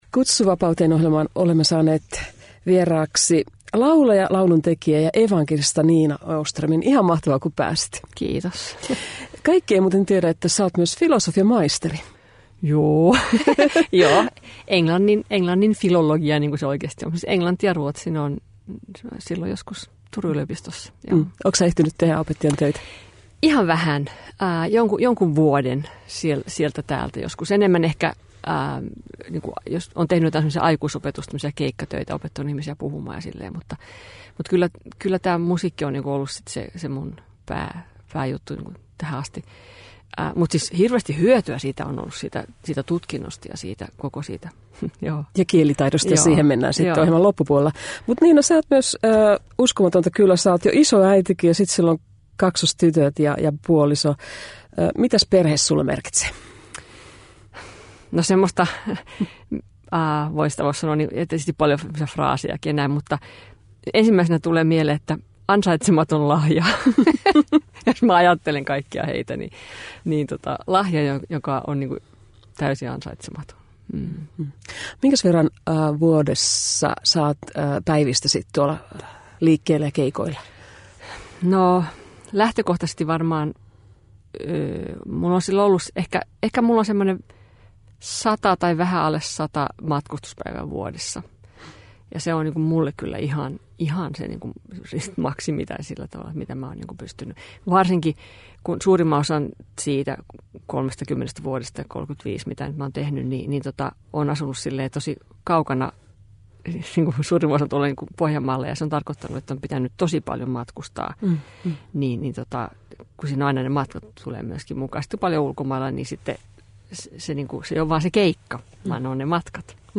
Nina vieraili Kutsu vapauteen -ohjelmassa 15. helmikuuta 2020.